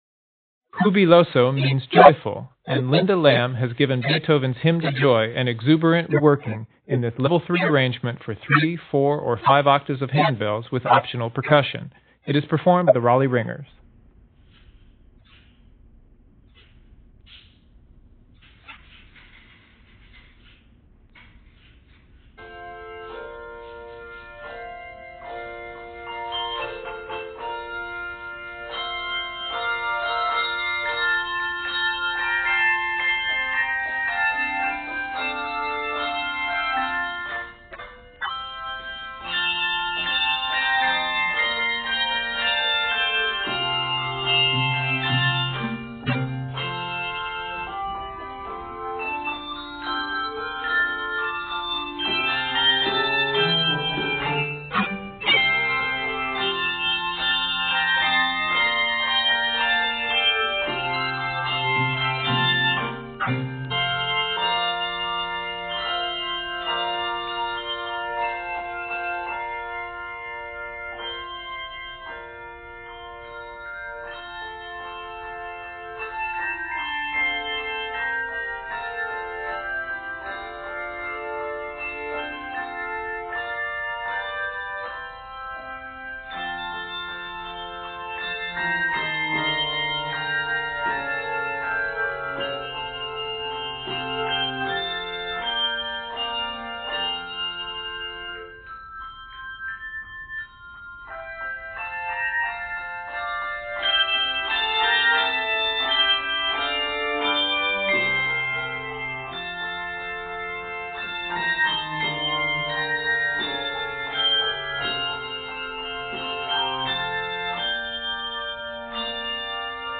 final fortississimo ending, complete with rousing shakes!